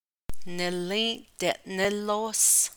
Lexical notes: The /sh/ in /Díísh jį́įdi/ is a ligature. It is inserted for pronunciation purposes, but does not have meaning.